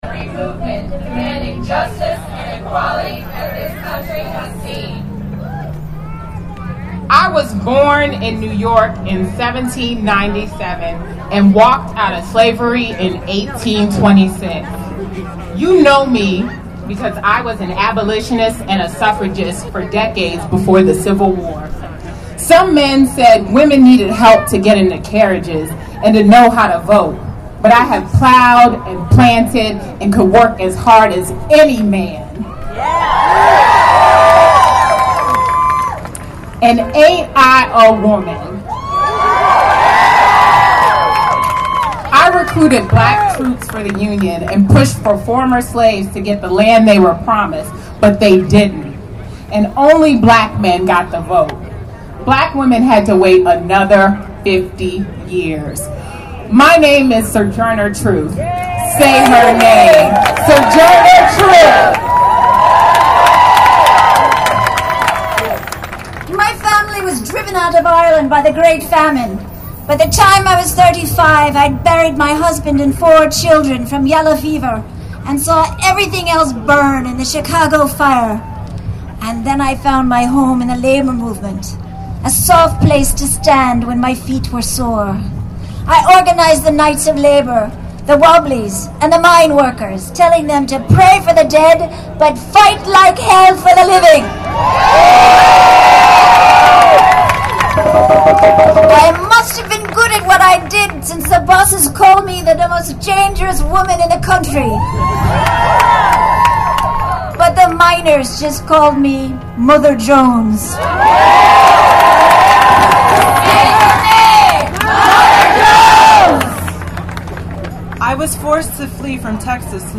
When the march reached a plaza with a podium, people were invited to reflect on the importance of the work of women in unions and their role in wage negotiations and in stopping the abuse of workers, all workers.
A number of women took the stage to honor the women who lost their lives in historical and contemporary struggles, shouting “Say her name!” Listen to their voices and say their names: